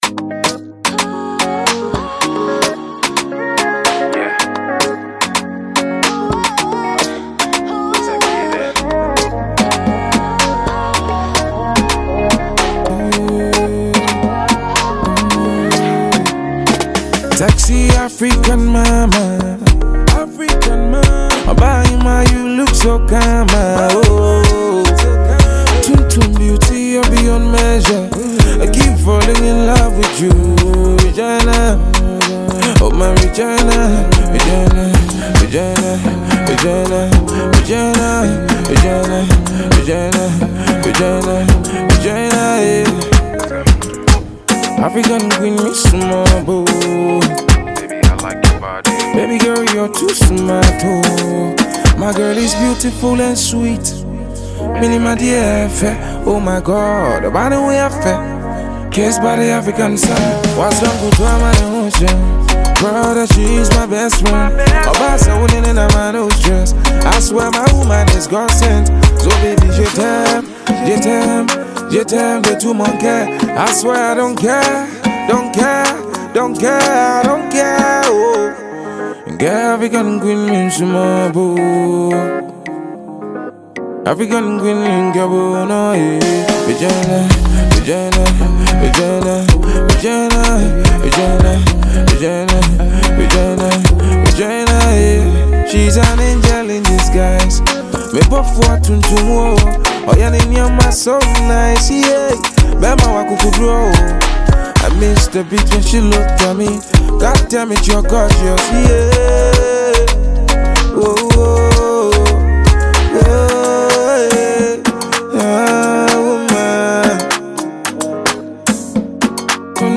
Ace Ghanaian singer and songwriter